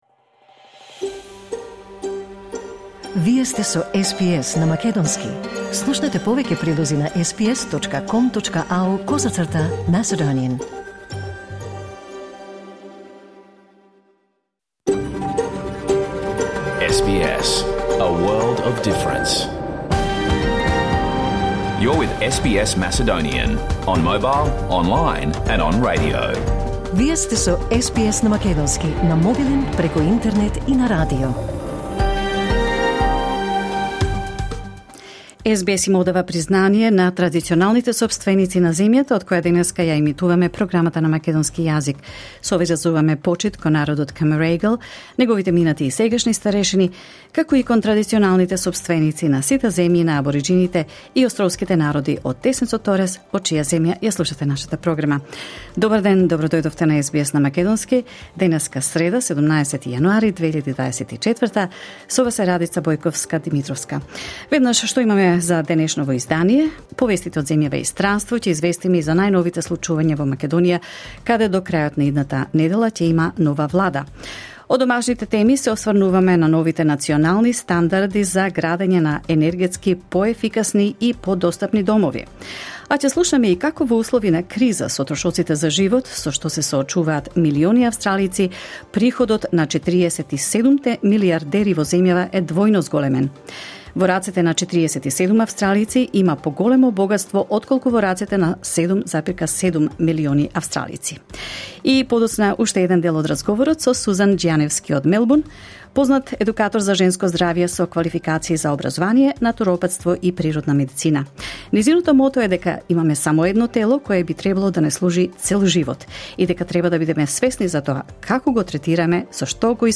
SBS Macedonian Program Live on Air 17 January 2024